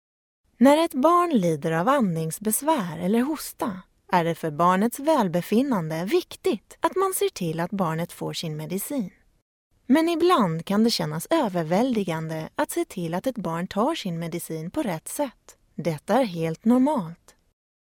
Swedish female voice over